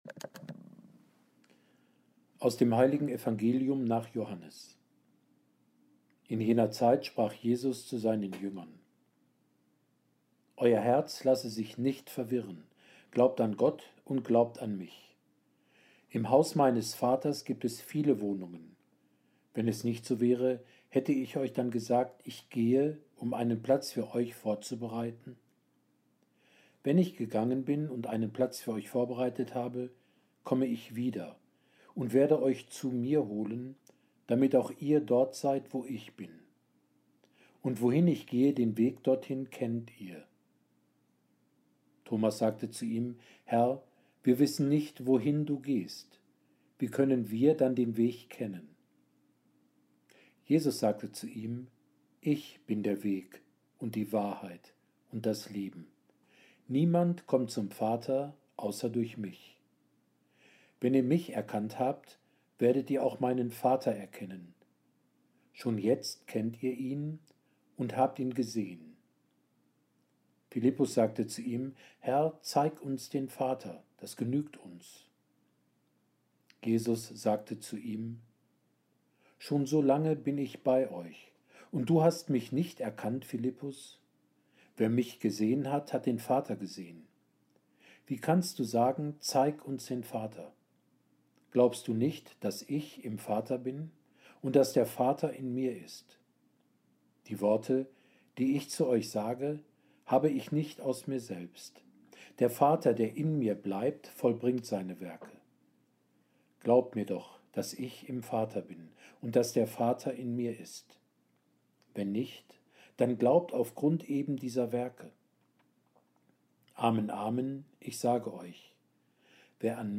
Impuls zum Fünften Sonntag der Osterzeit